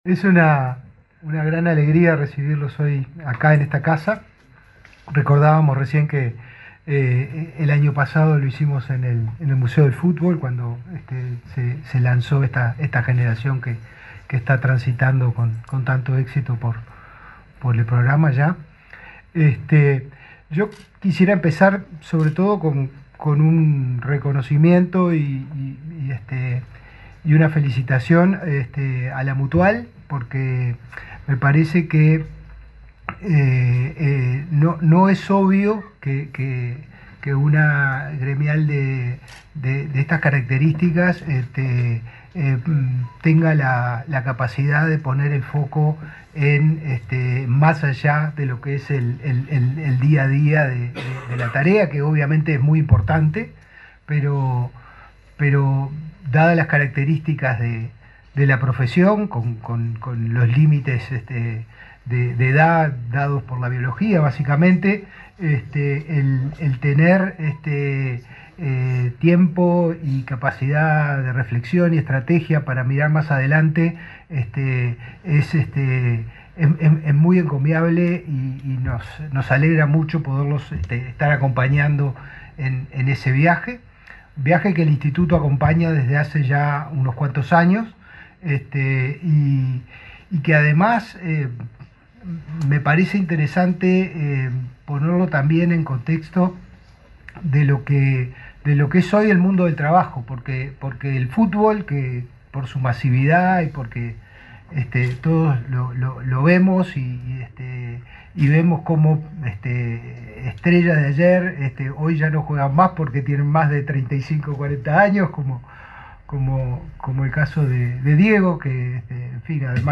Palabras de autoridades en firma de convenio en Inefop